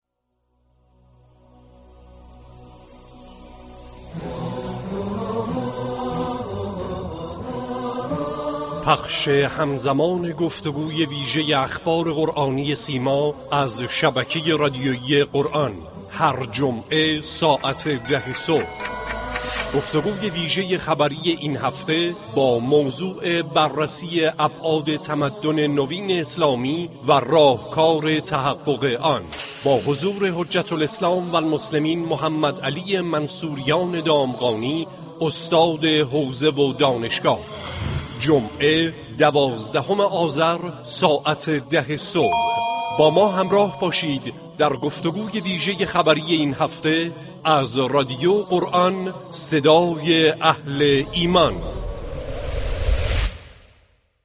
یادآور می‌شود، گفت‌وگوی جمعه اخبار قرآنی هر هفته به صورت زنده، پیرامون یكی از موضوعات قرآنی و دینی با اجرا و كارشناسی